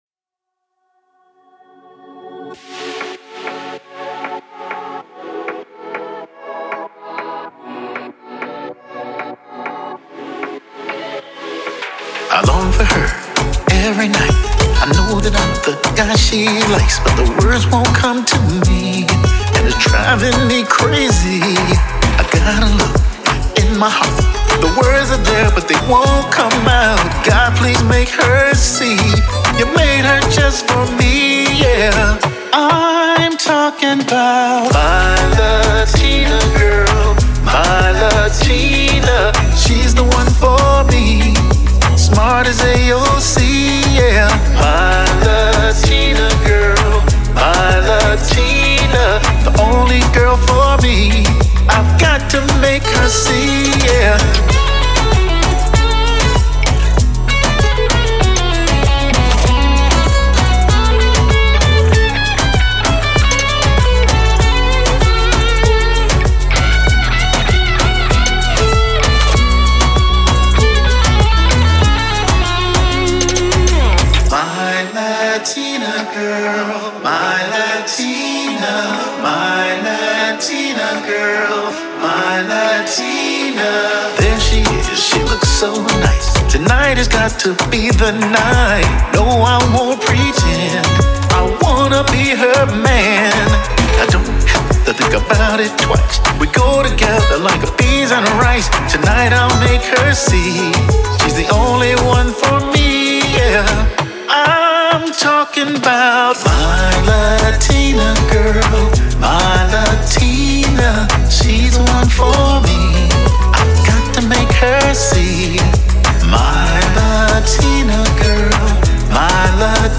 playful, yet sexy homage to hispanic women